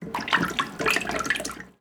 household
Toilet Water Splash 2